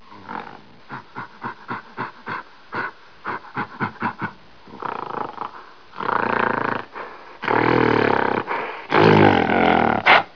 جلوه های صوتی
دانلود صدای حیوانات جنگلی 28 از ساعد نیوز با لینک مستقیم و کیفیت بالا
برچسب: دانلود آهنگ های افکت صوتی انسان و موجودات زنده دانلود آلبوم صدای حیوانات جنگلی از افکت صوتی انسان و موجودات زنده